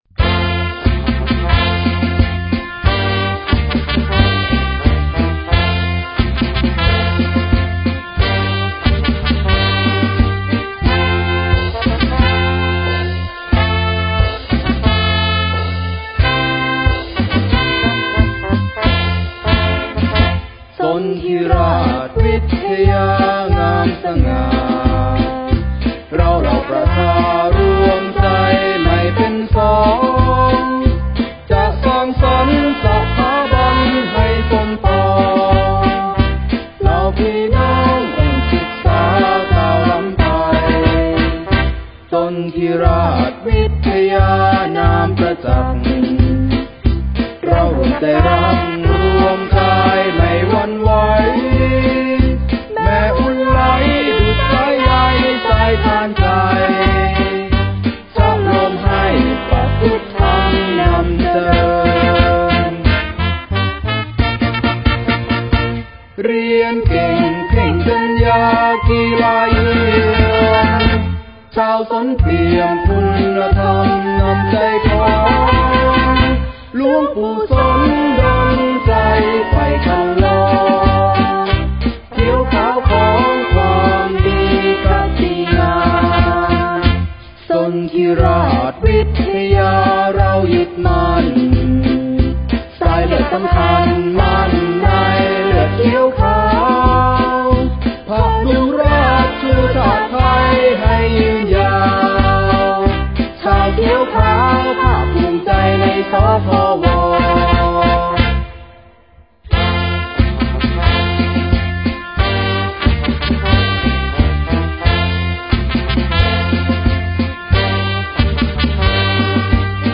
เพลงโรงเรียน